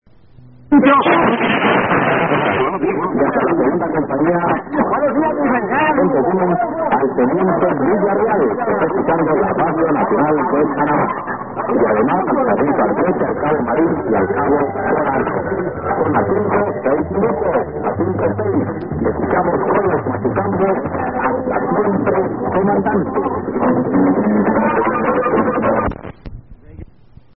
All recordings were made in Santa Barbara, Honduras (SB) using a Yaesu FRG-7 receiver.